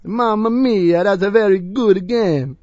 gutterball-3/Gutterball 3/Commentators/Louie/l_mamameeah.wav at f3327c52ac3842ff9c4c11f09fb86b6fc7f9f2c5
l_mamameeah.wav